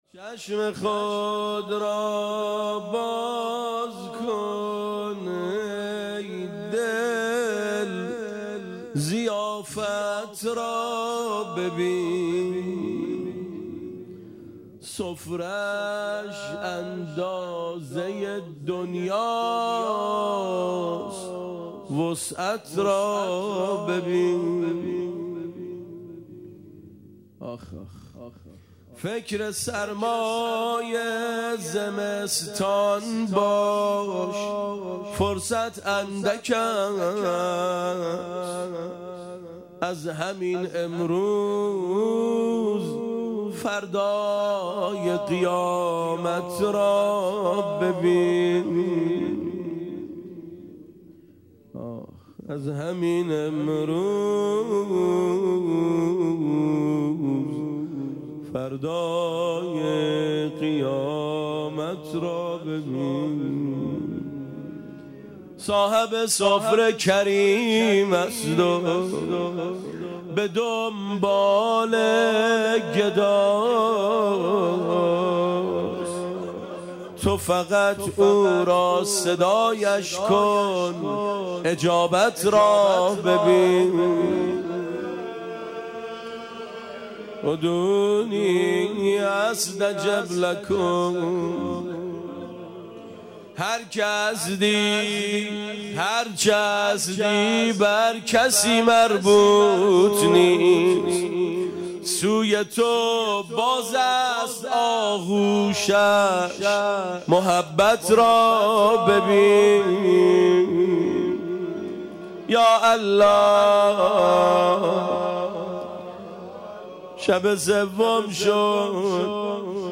زمینه چشم خود را باز کن ای دل، ضیافت را ببین
شب سوم ماه رمضان